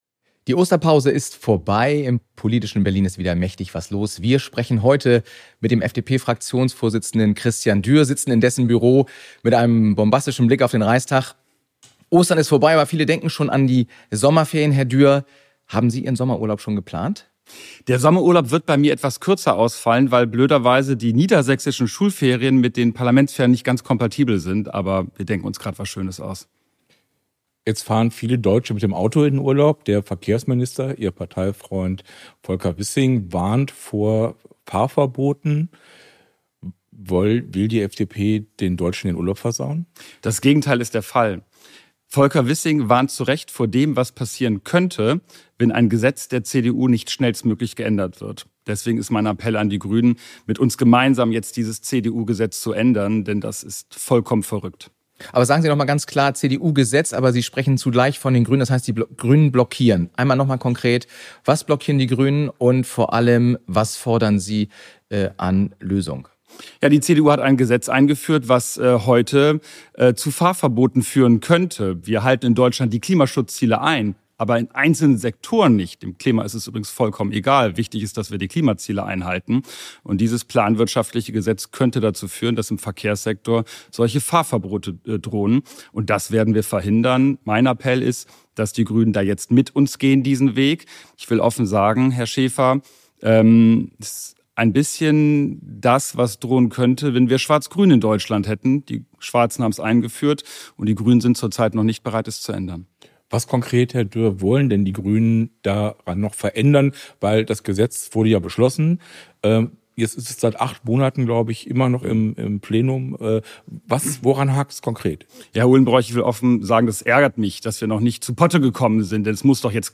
im Gespräch mit FDP-Fraktionschef Christian Dürr.